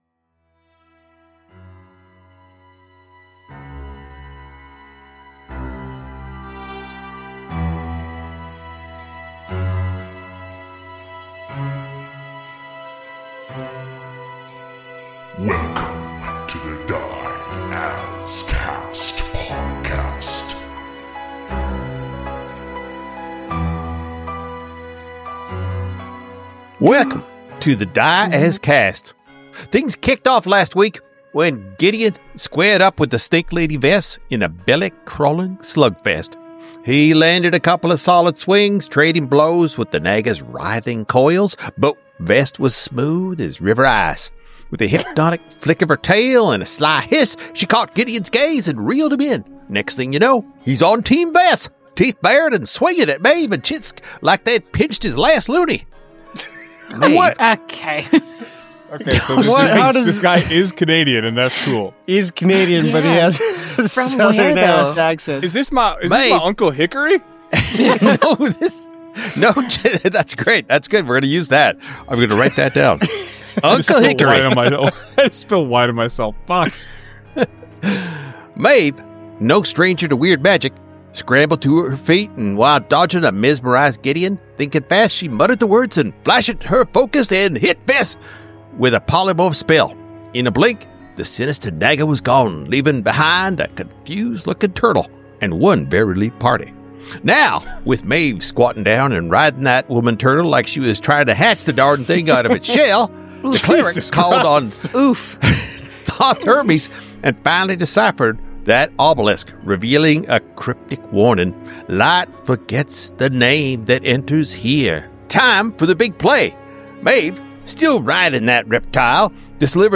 Welcome to a brand new Dungeons & Dragons 5e actual-play Podcast.